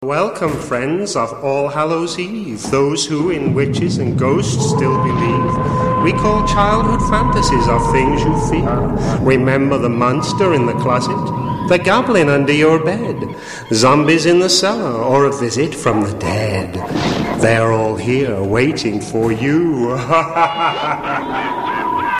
Tags: Voice Talent st.patrick saint patrick Voice actor